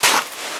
STEPS Sand, Walk 08.wav